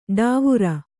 ♪ d`āvura